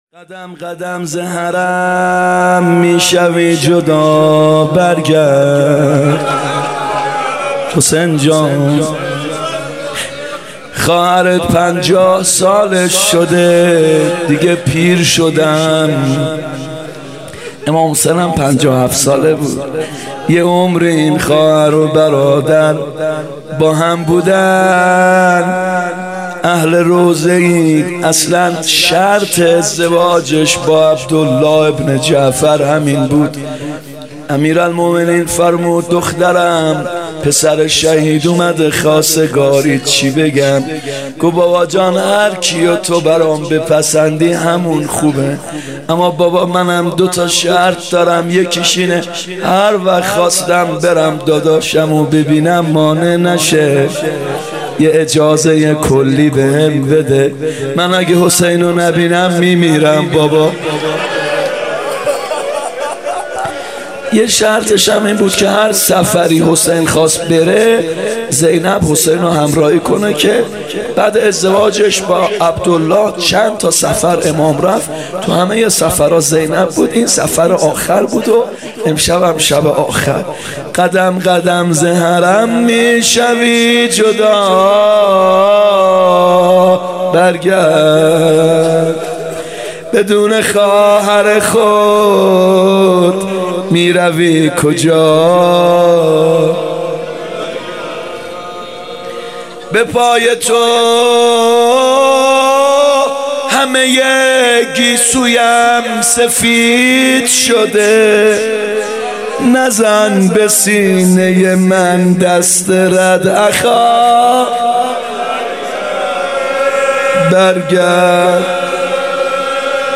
محرم 95(هیات یا مهدی عج)